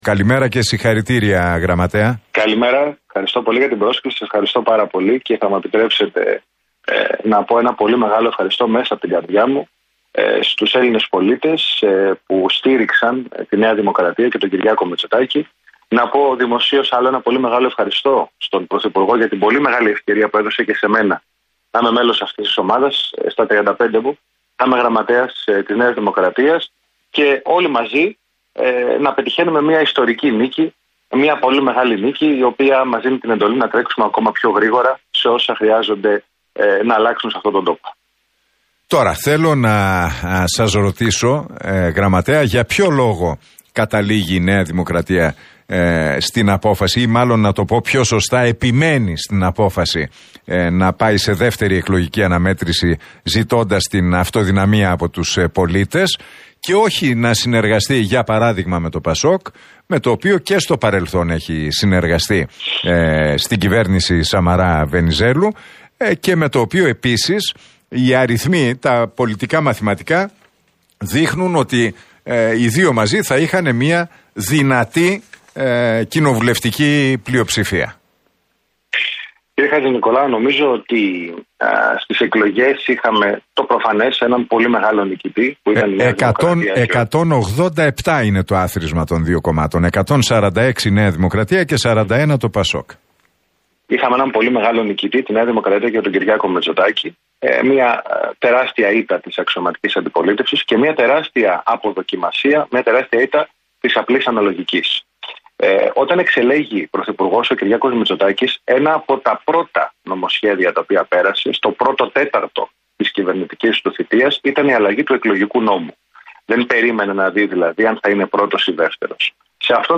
Μία ημέρα μετά την μεγάλη νίκη της ΝΔ στις εκλογές, ο γραμματέας του κόμματος, Παύλος Μαρινάκης, μίλησε στην εκπομπή του Νίκου Χατζηνικολάου στον Realfm 97,8.